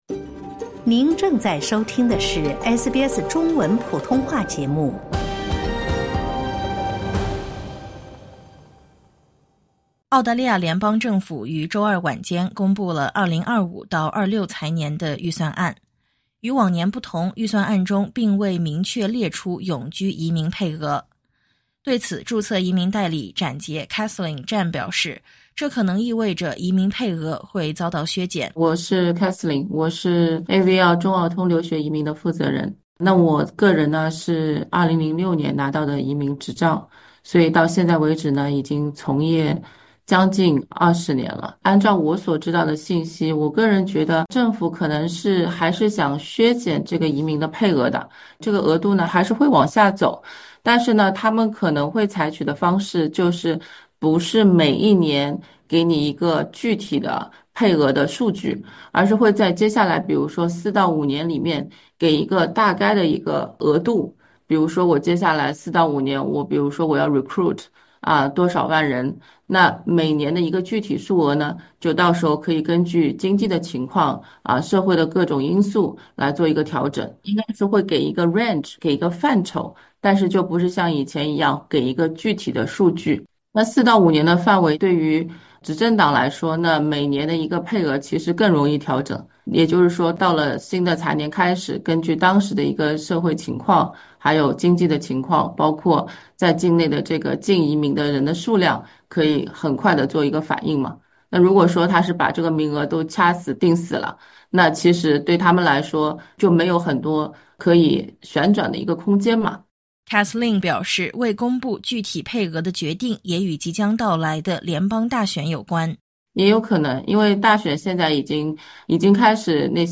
2025联邦预算案为何未明确永居移民配额？这对未来移民政策有何影响？点击音频，收听注册移民代理解读。